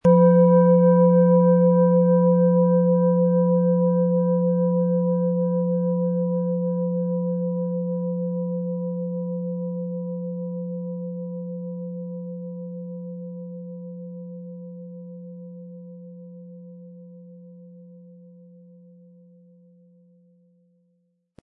Planetenton 1
Ein die Schale gut klingend lassender Schlegel liegt kostenfrei bei, er lässt die Planetenklangschale Sonne harmonisch und angenehm ertönen.
MaterialBronze